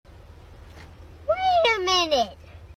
Download funny sound titled “Wait A Minute” (meme for Comedy) 😃